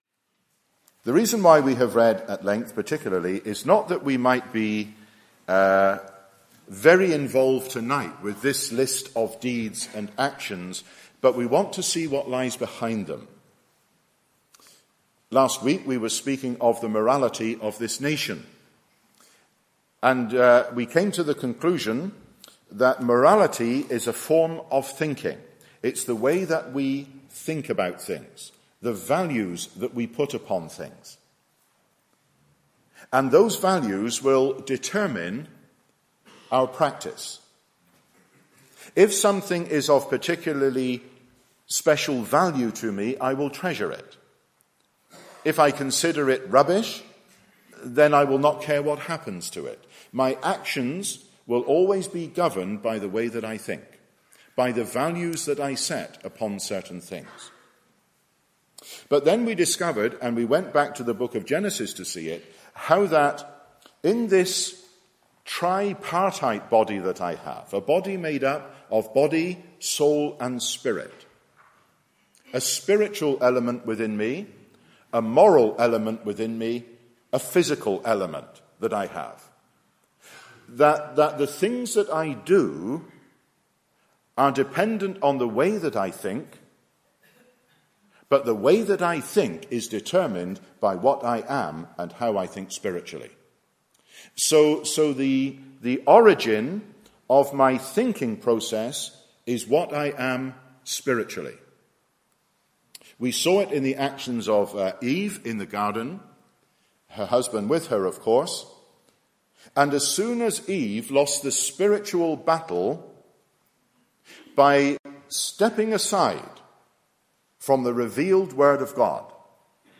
The new birth brings about a change of life that evidences a real work of God in salvation. (Message preached in Ballymena, 29th Nov 2008).